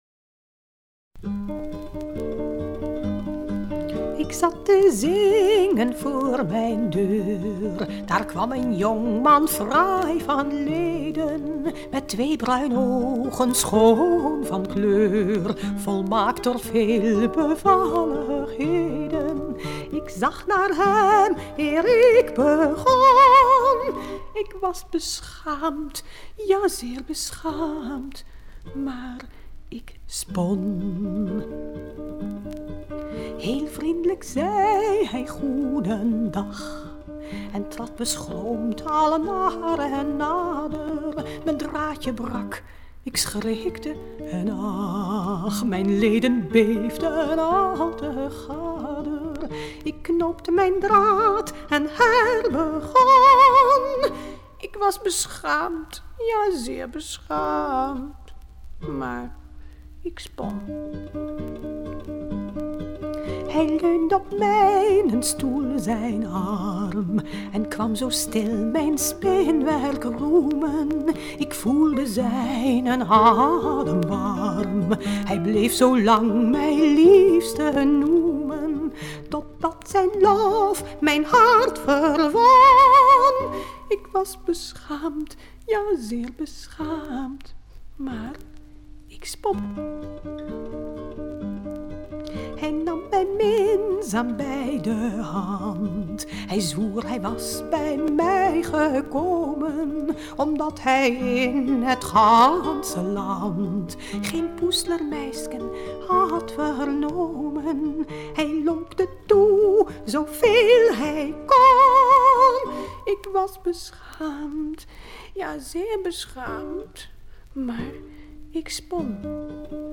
gitaar